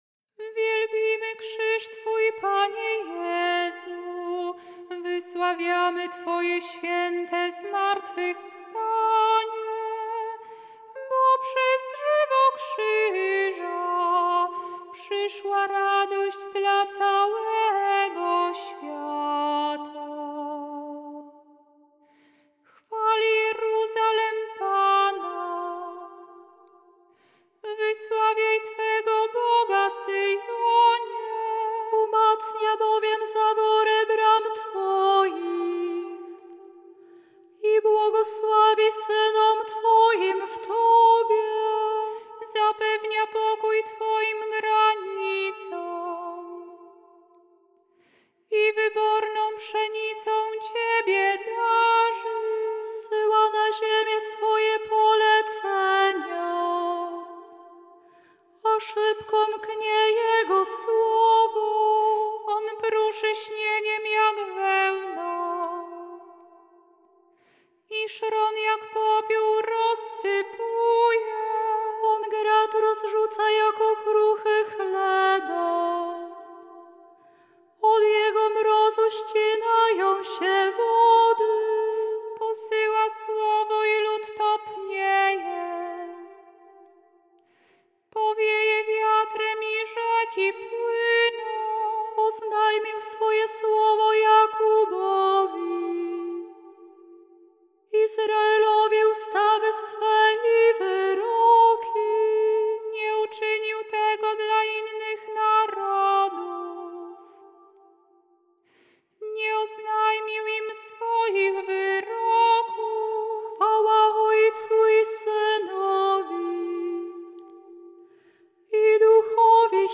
Dlatego do psalmów zastosowano tradycyjne melodie tonów gregoriańskich z ich różnymi, często mniej znanymi formułami kadencyjnymi (tzw. dyferencjami).
Dla pragnących przygotować się do animacji i godnego przeżycia tych wydarzeń liturgicznych przedstawiamy muzyczne opracowanie poszczególnych części wykonane przez nasze siostry